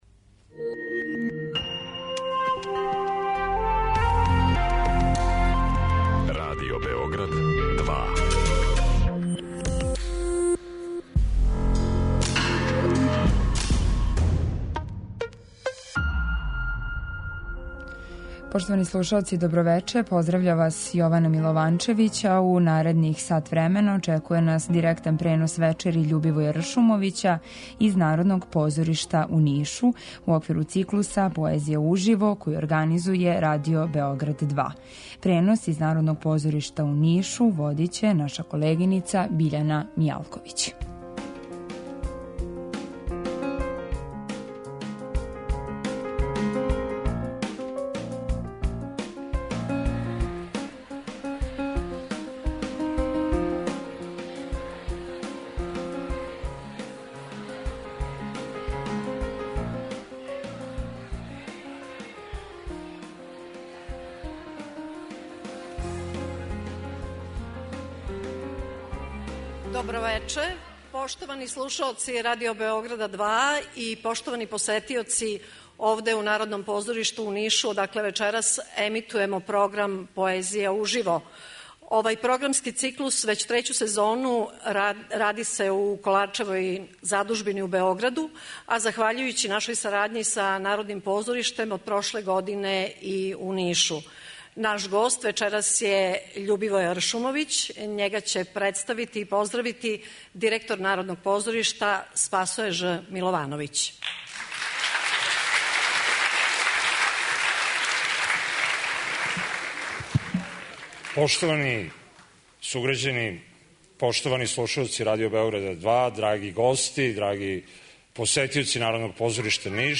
Поезија уживо! - Вече Љубивоја Ршумовића у Нишу
Понедељак, 28. октобар у 19 часова, Народно позориште у Нишу
poezija niš.mp3